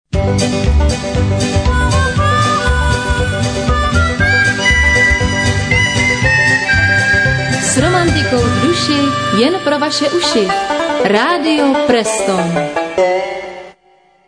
Jingle...
Jeden z nejhezčích jinglů...